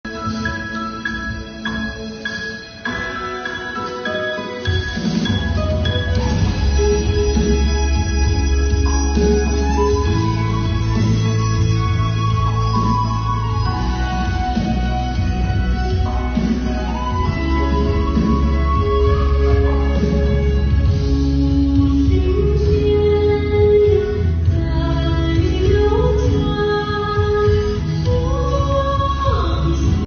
中国音乐家协会理事、著名歌唱家乌兰图雅还将倾情献唱，快来叫醒耳朵！